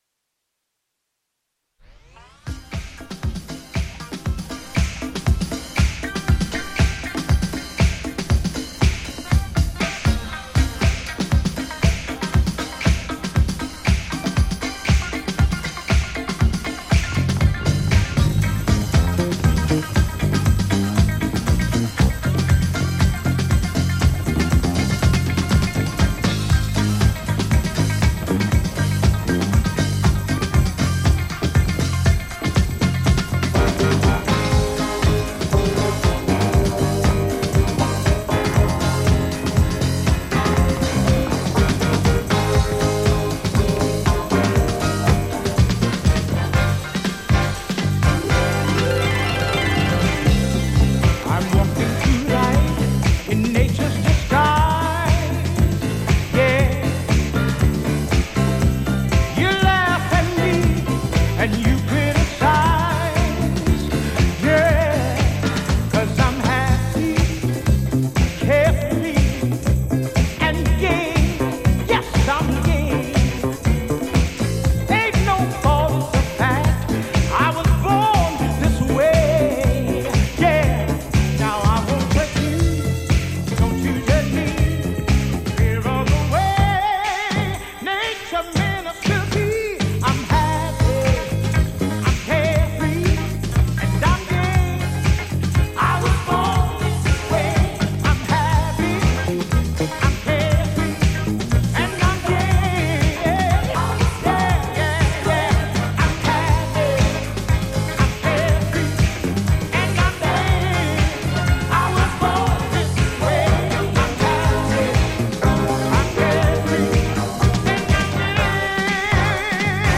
ジャンル(スタイル) DISCO / GARAGE / DANCE CLASSIC / REISSUE